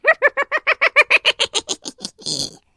На этой странице собраны забавные звуки гномов: смех, шутки, шаги и другие загадочные шумы.
Зловещий смех гнома (он задумал нечто плохое)